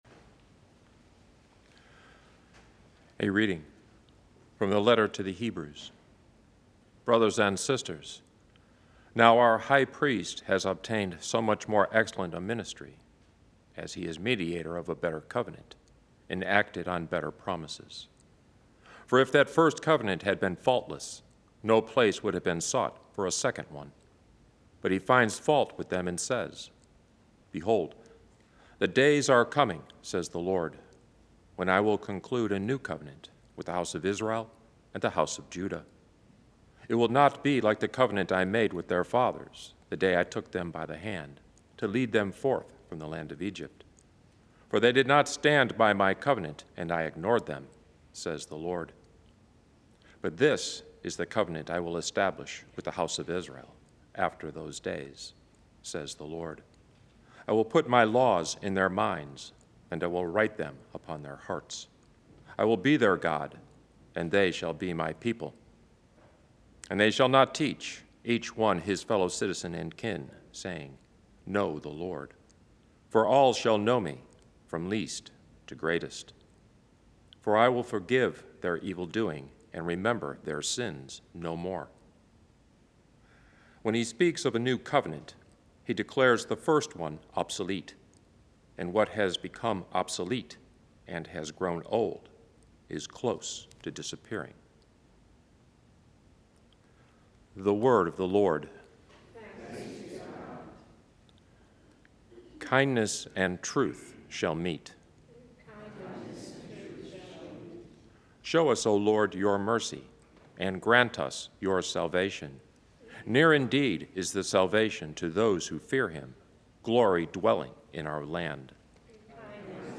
Advent Weekday